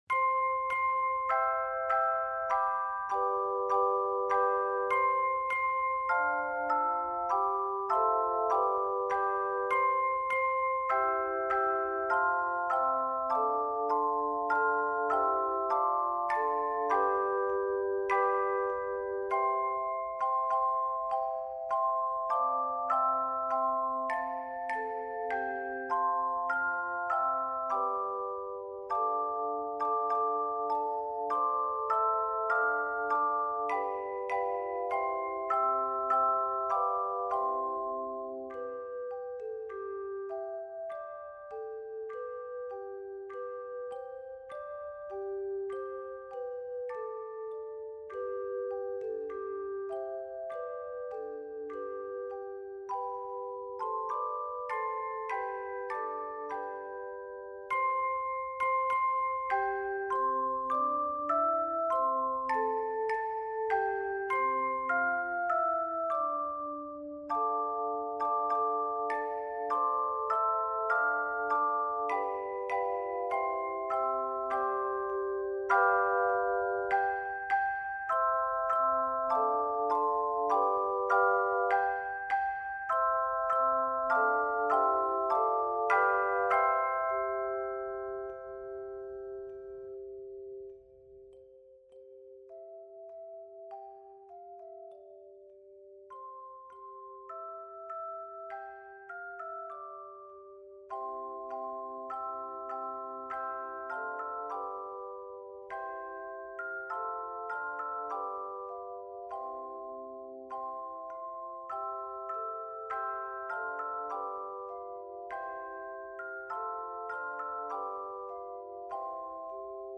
Both are scored in C Major.